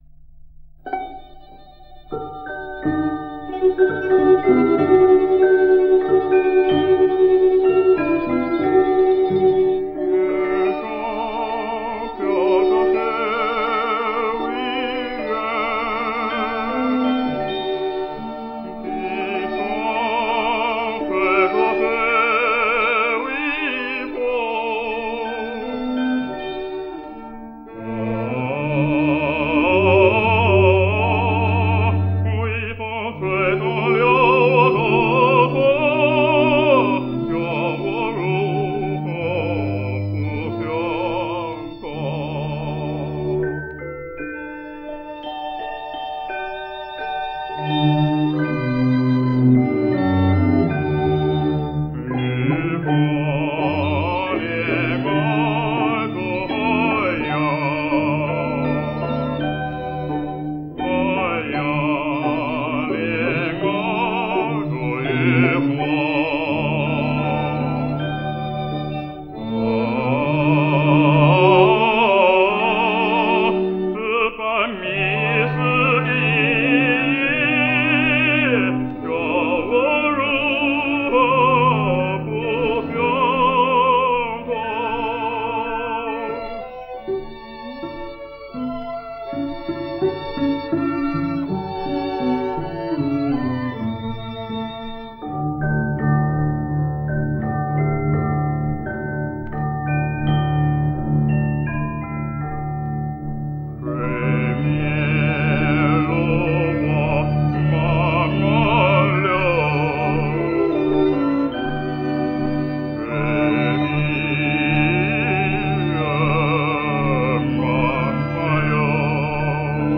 应考虑到歌曲都是几十年前的制作，希望友调整一下自己的“金耳朵”，